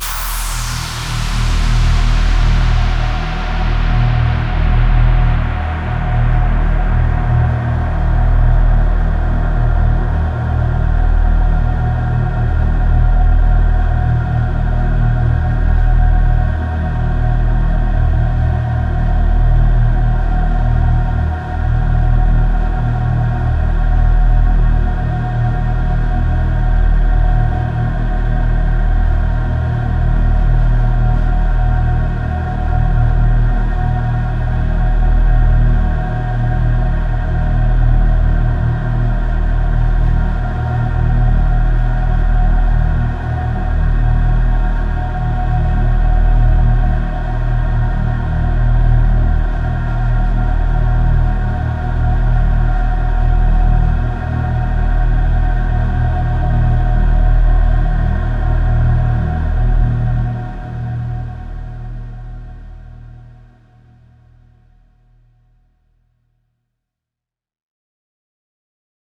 DRONE-037-HOLY-DOOM-DRONE-136BPM-Eb